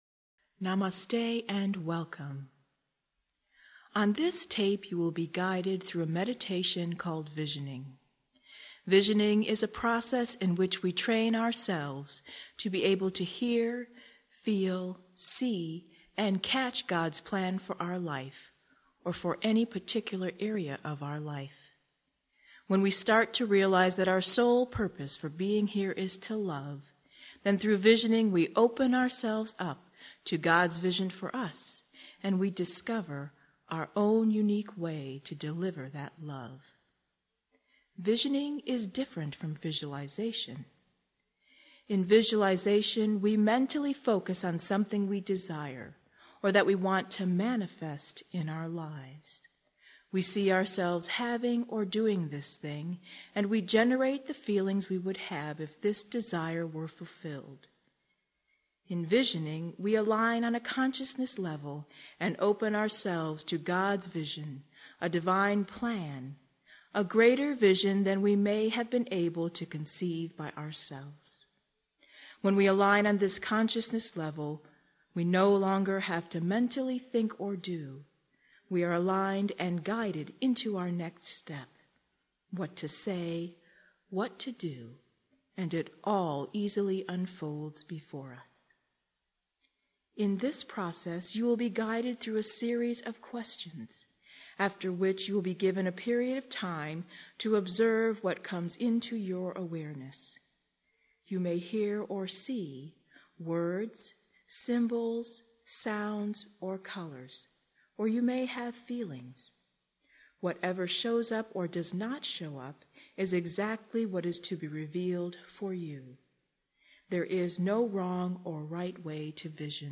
Visioning Meditation - Downloadable MP3- only $5.99